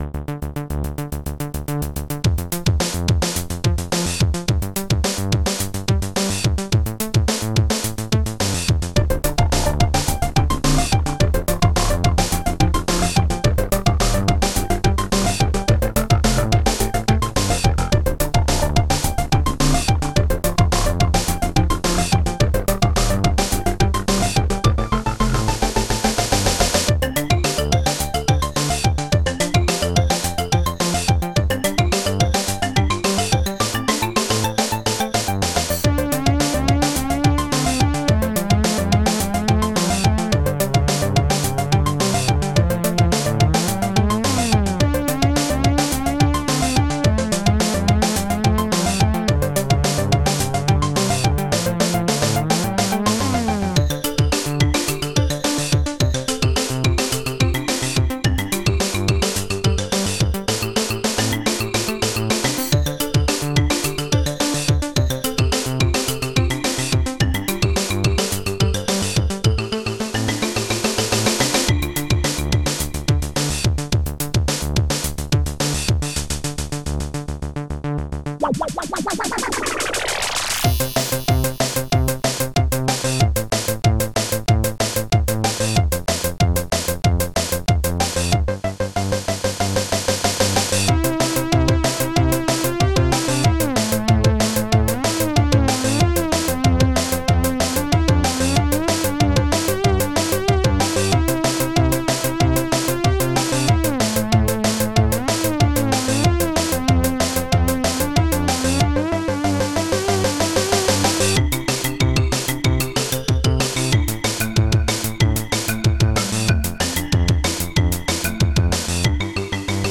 440-effect drum
snare 4567
longcymbal
shortcimbal
labersynth
xylophone